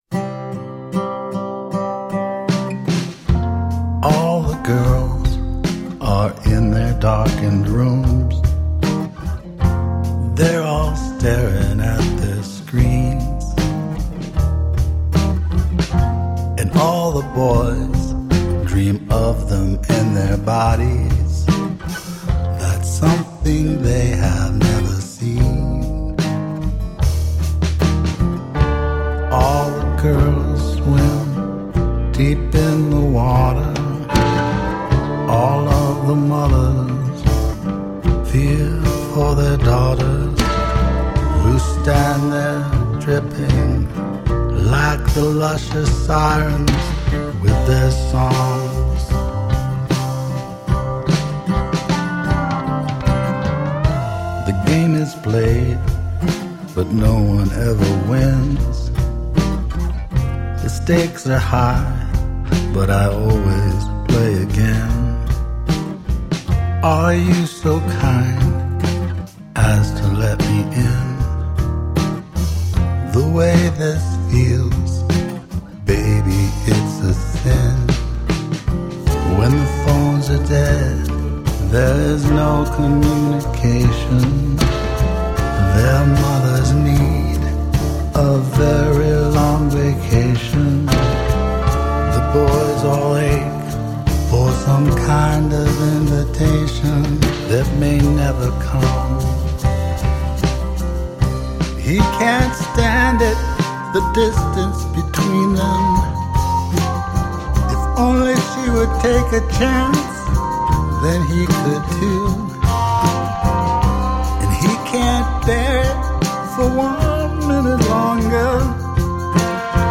An introspective mix of acoustic guitar based songs.
The tracks are arranged around acoustic guitars, mostly.